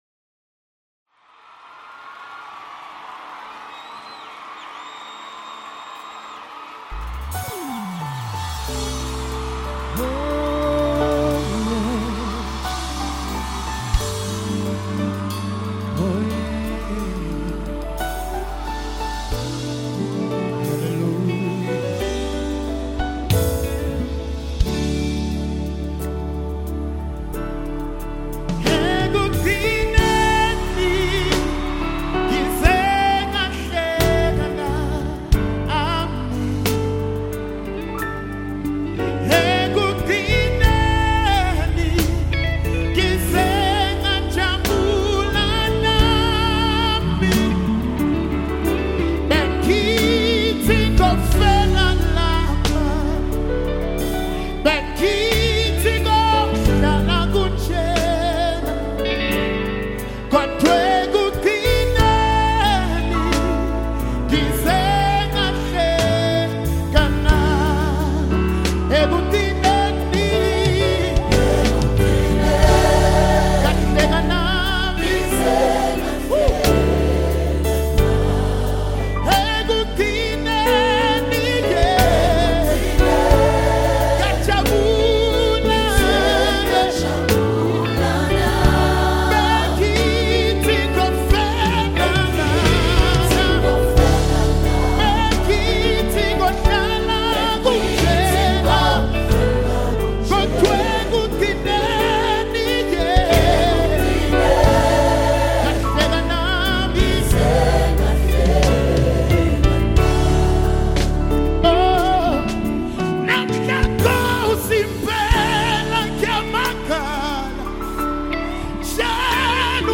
South African Gospel Music